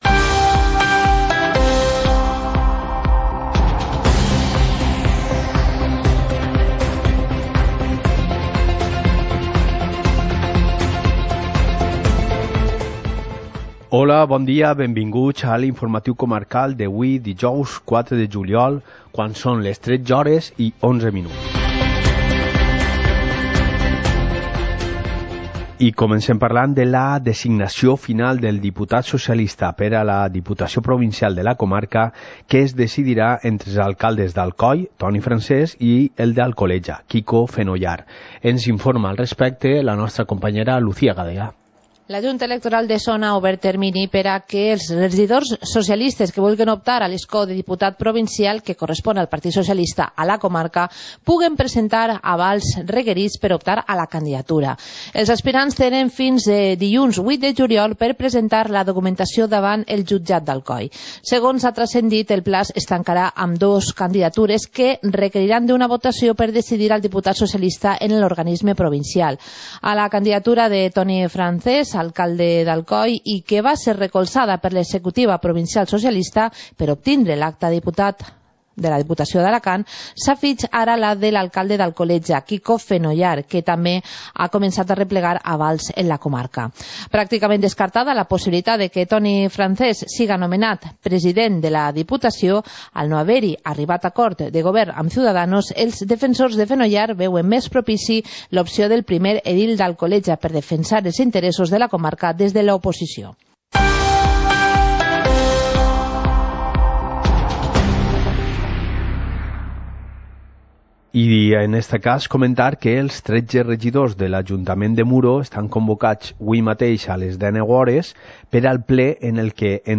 Informativo comarcal - jueves, 04 de julio de 2019